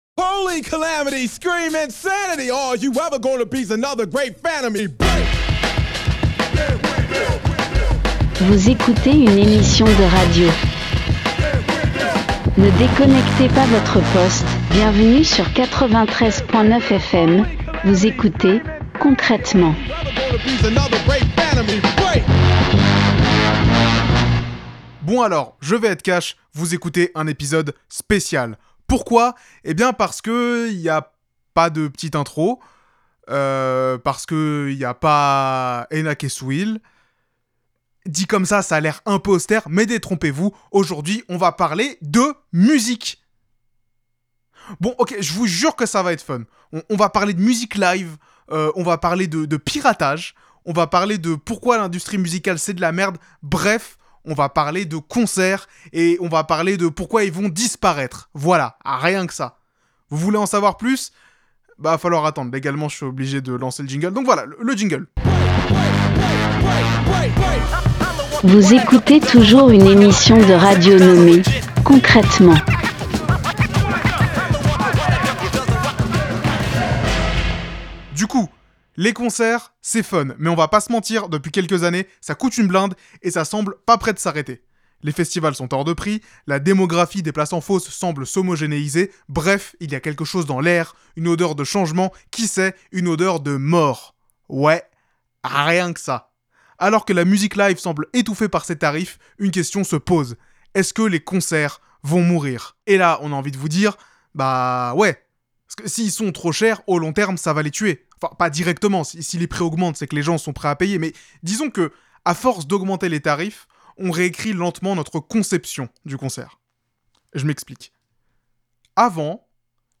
Partager Type Création sonore Société vendredi 4 avril 2025 Lire Pause Télécharger Aujourd'hui les concerts sont chers, hier ils ne l'étaient pas.